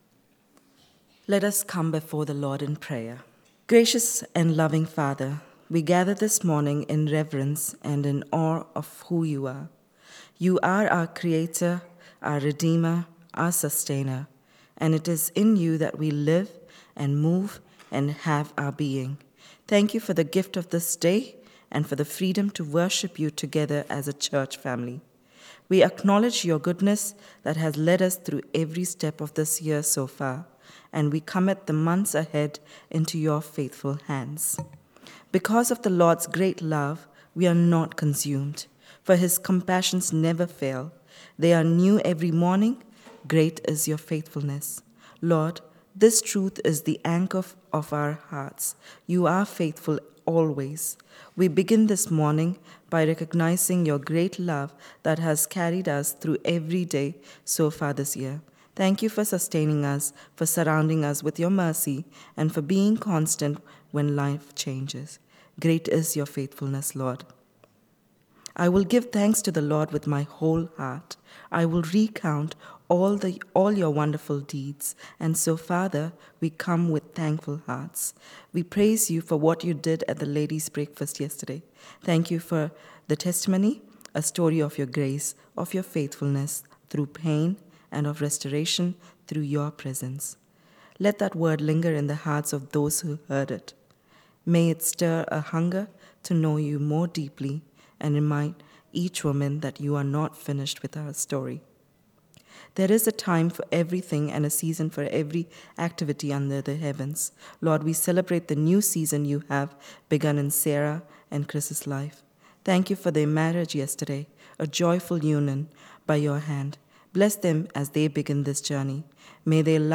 Opening Prayer